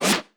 random-spin.wav